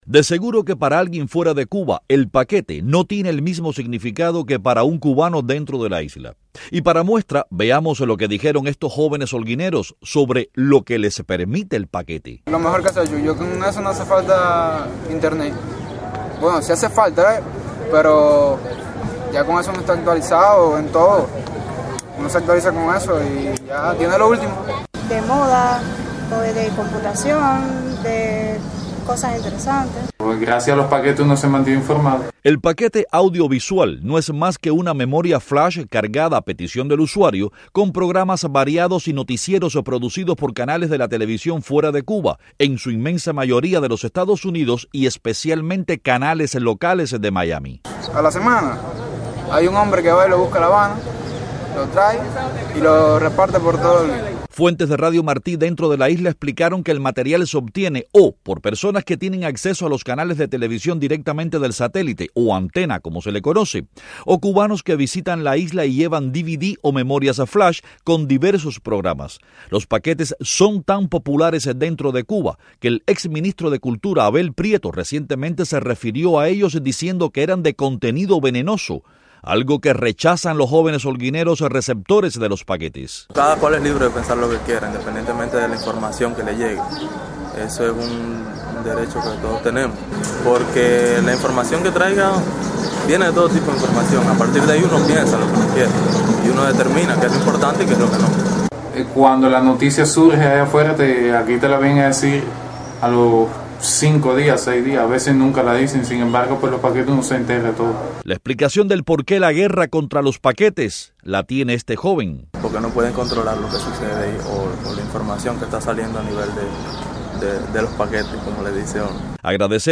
a partir de los testimonios de residentes de la provincia de Holguín.